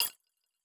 Futuristic Sounds (18).wav